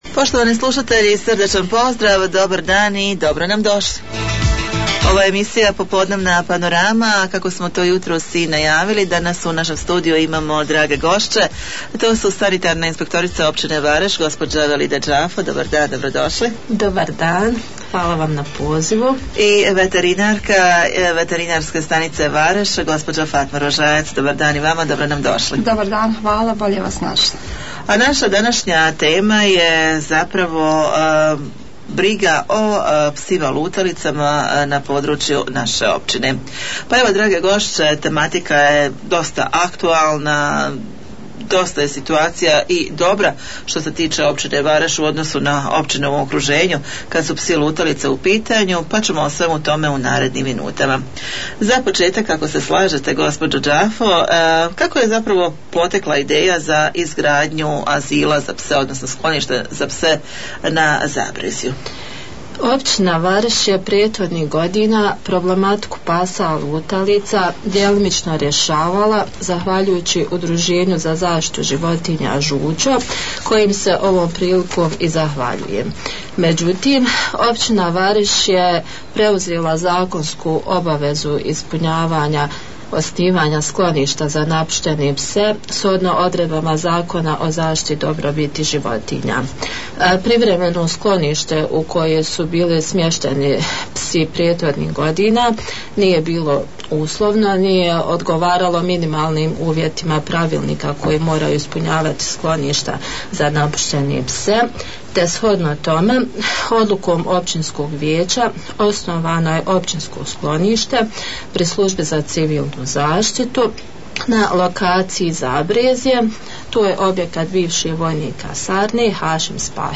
U studiju smo razgovarali